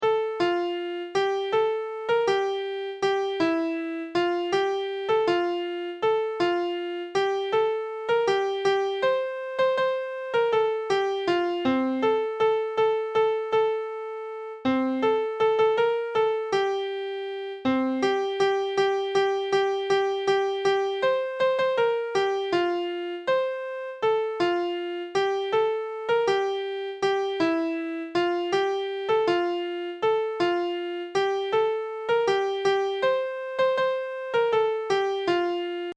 American Singing Game